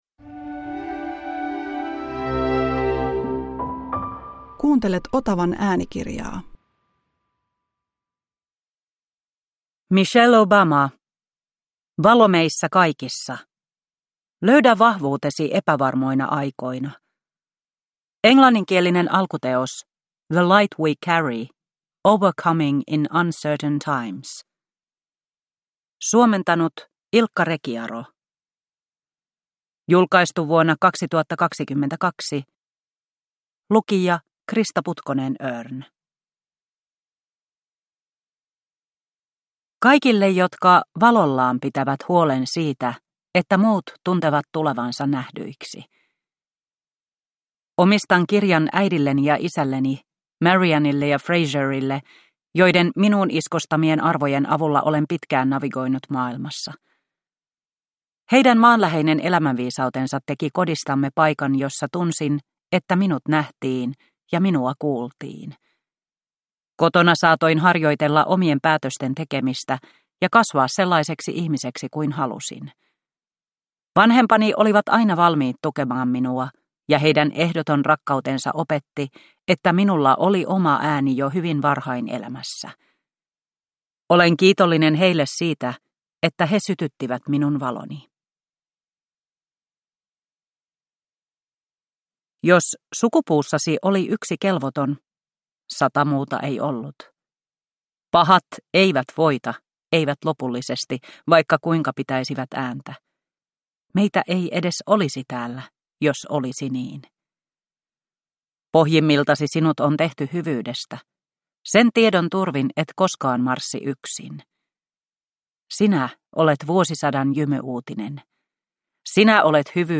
Valo meissä kaikissa – Ljudbok – Laddas ner